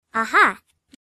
Звуки человека, аха
• Качество: высокое
Детское аха